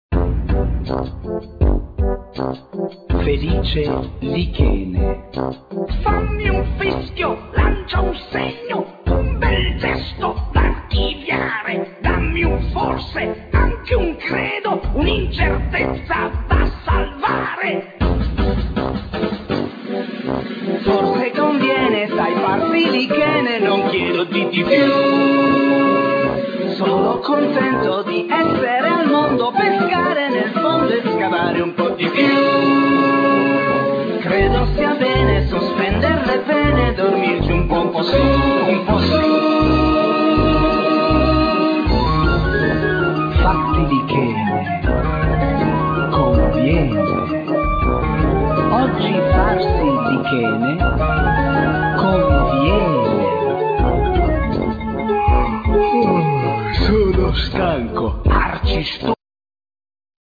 Piano,Keyboards
Vocals
El. bass,Double bass
El. + Ac.guiatrs
Drums